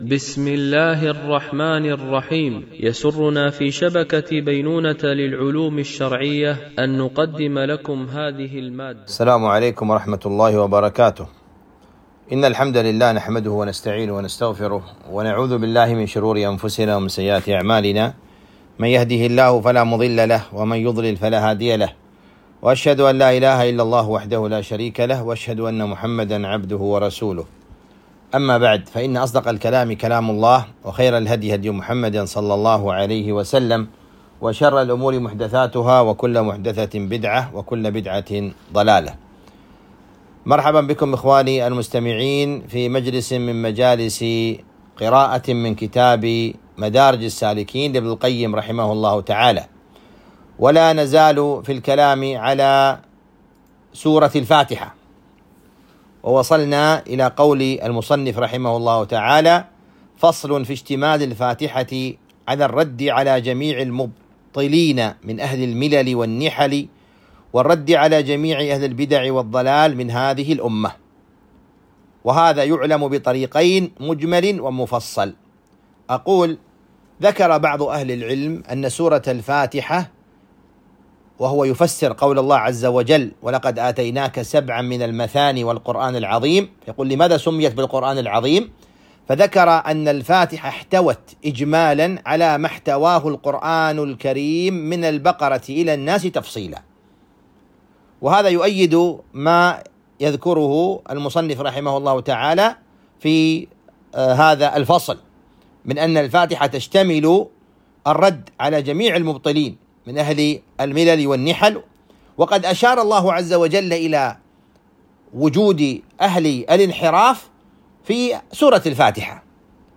قراءة من كتاب مدارج السالكين - الدرس 07